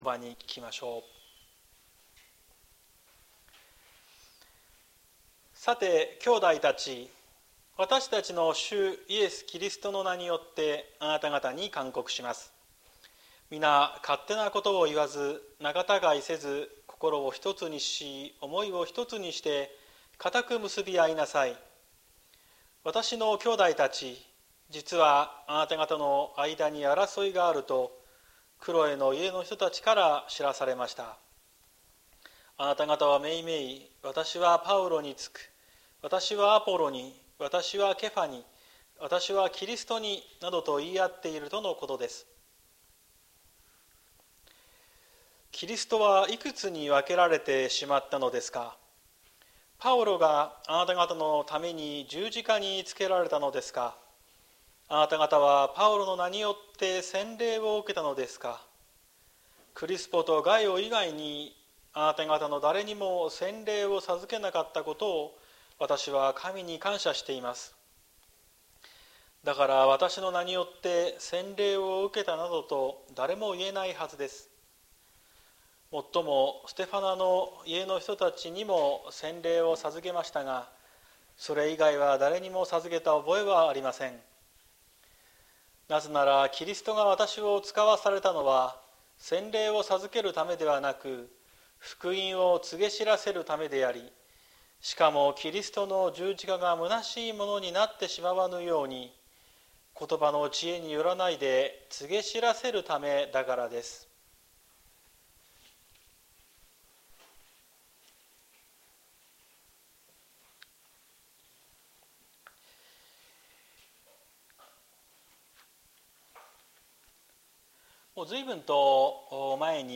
綱島教会。説教アーカイブ。